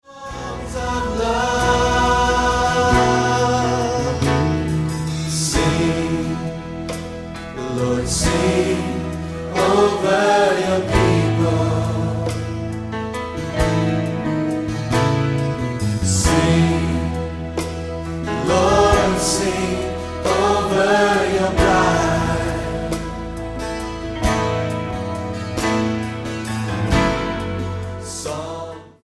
STYLE: MOR / Soft Pop
The musical arrangements are slick, but not too much so.